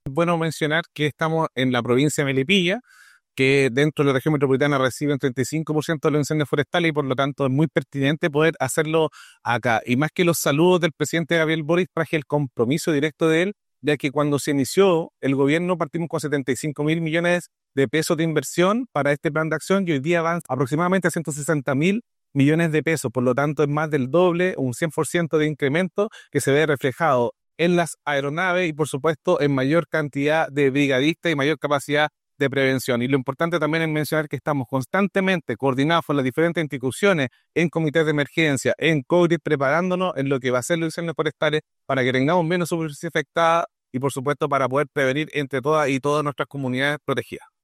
En el aeródromo de Curacaví se llevó a cabo el lanzamiento del Plan Regional de Acción de Prevención, Mitigación y Control de Incendios Forestales 2025-2026, actividad que contó con la presencia del delegado presidencial provincial de Melipilla, Bastián Alarcón; el director ejecutivo de CONAF, Rodrigo Illesca; la directora regional de CONAF Metropolitana, Elke Huss; y el director regional de SENAPRED, Miguel Muñoz, participando diversas autoridades, entre otros servicios públicos, privados y voluntariados que son parte de la emergencia.